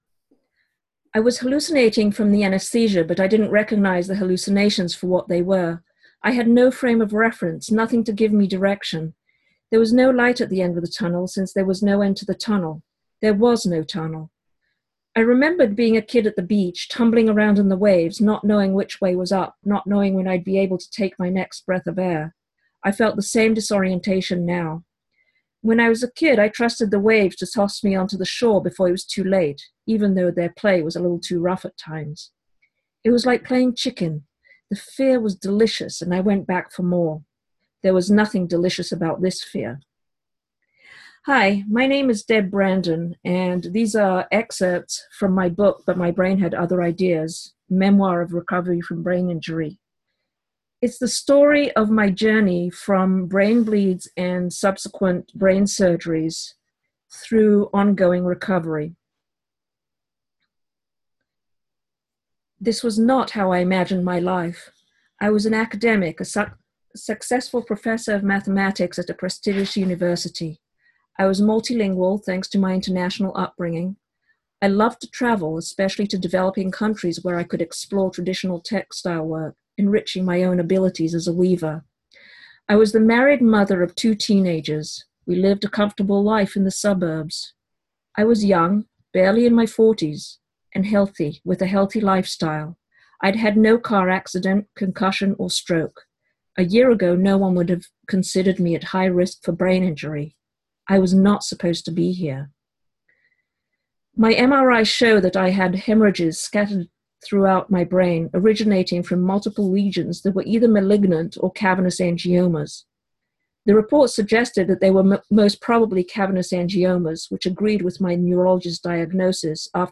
An excerpt reading